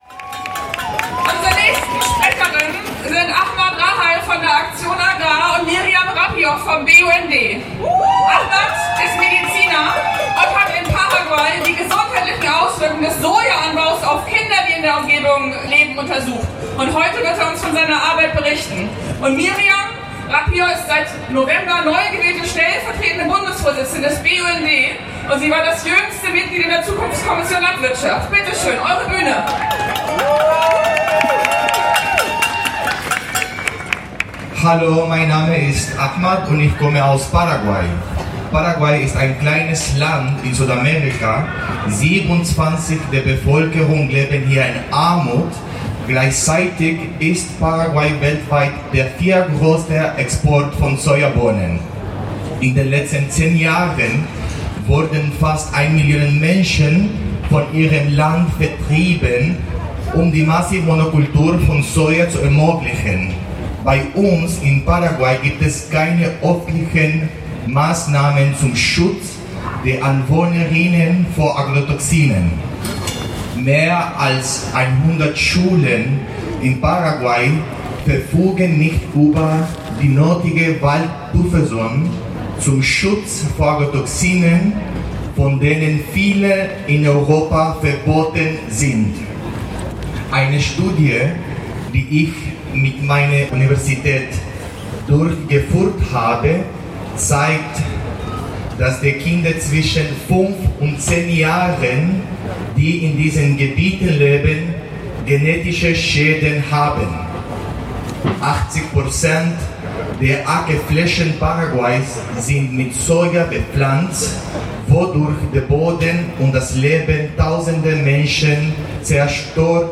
Abschlusskundgebung
Der zweite Teil des Bühnenprogramms